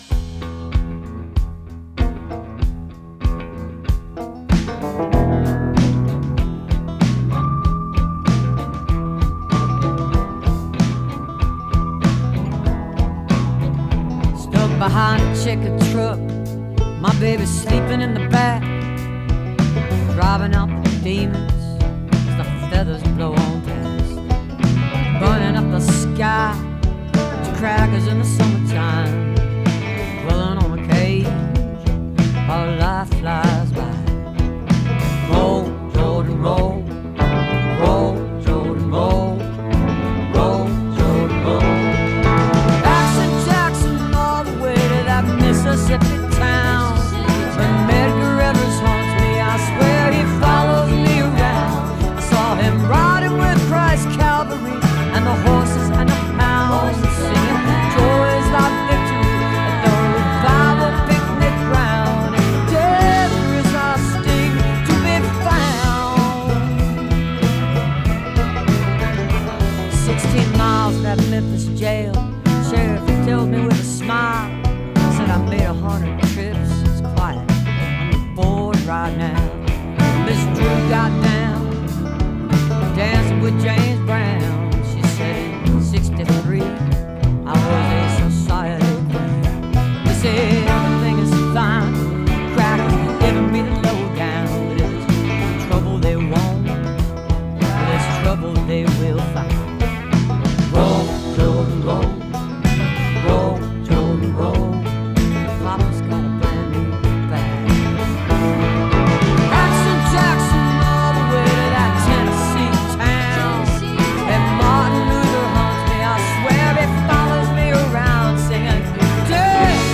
(captured from webcast)
album version